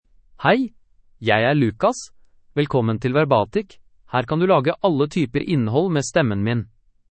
Lucas — Male Norwegian Bokmål AI voice
Lucas is a male AI voice for Norwegian Bokmål (Norway).
Voice: LucasGender: MaleLanguage: Norwegian Bokmål (Norway)ID: lucas-nb-no
Voice sample
Listen to Lucas's male Norwegian Bokmål voice.